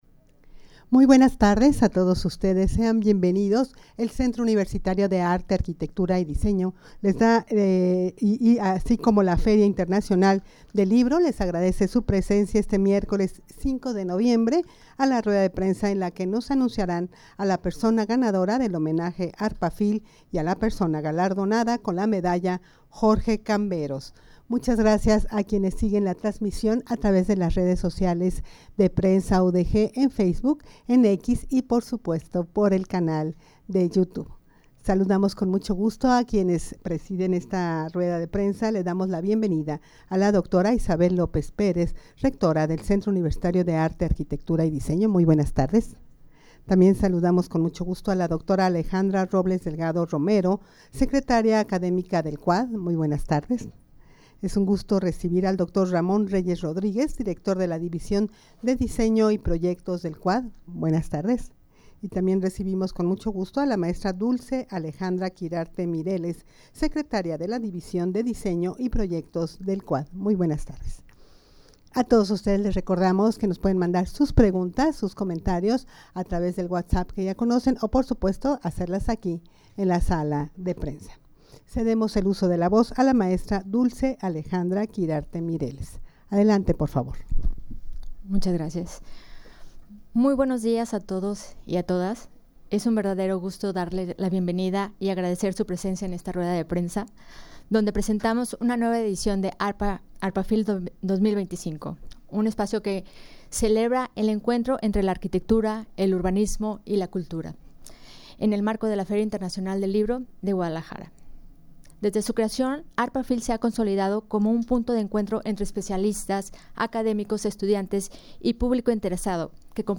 Audio de la Rueda de Prensa
rueda-de-prensa-para-anunciar-a-la-persona-ganadora-del-homenaje-arpafil.mp3